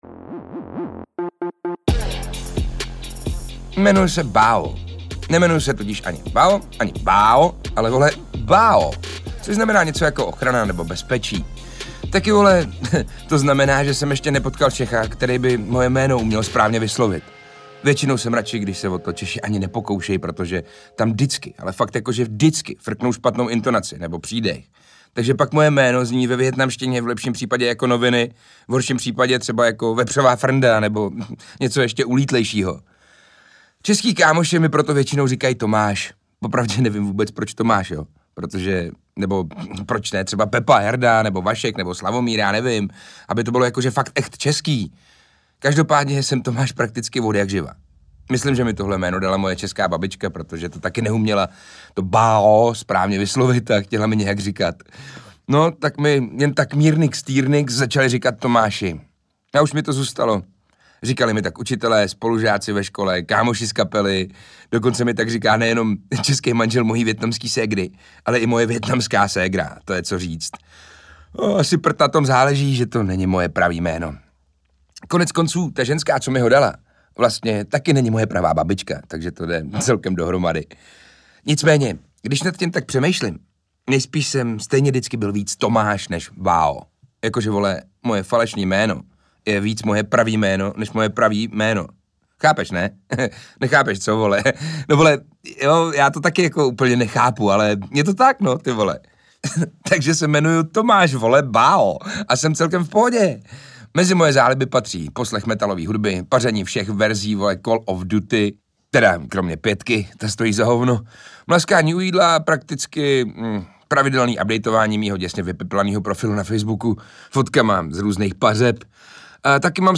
Nihilista na balkonu je audiokniha, která obsahuje pět soudobých humoristických novel na pomezí fantaskna a satiry.
Ukázka z knihy